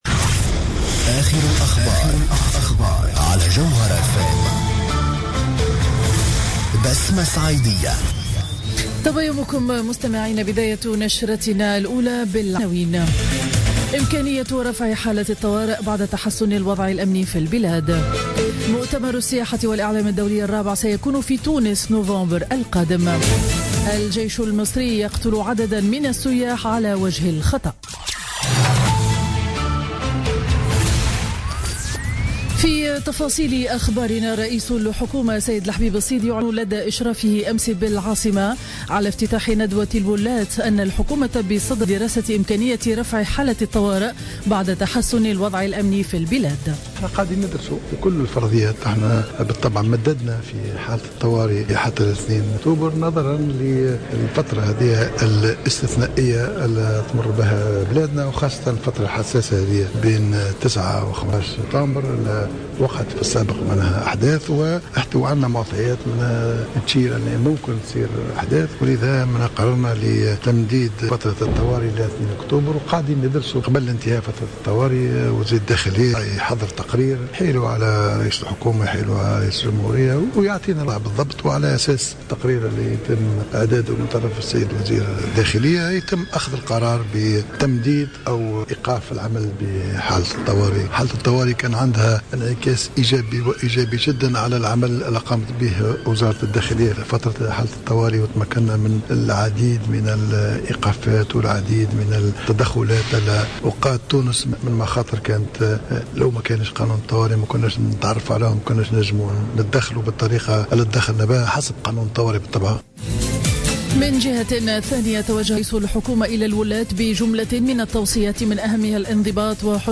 نشرة أخبار السابعة صباحا ليوم الاثنين 14 سبتمبر 2015